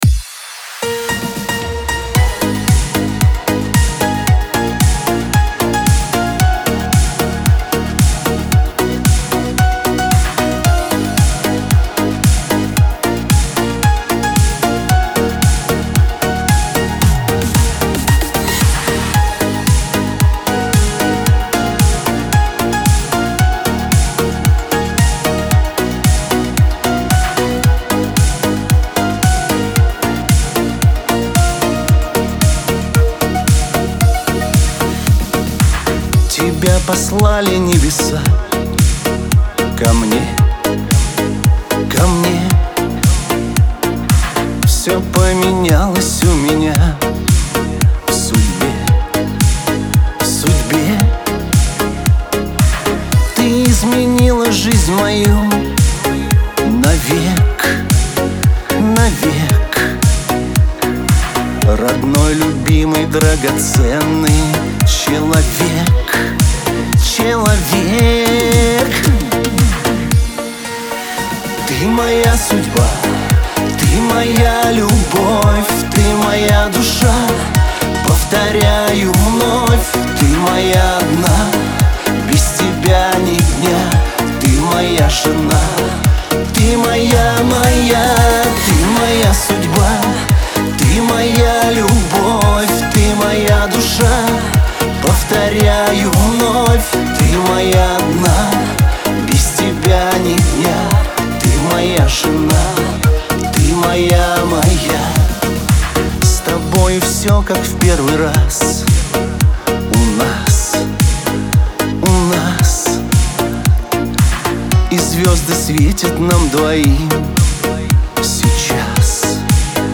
pop , диско
эстрада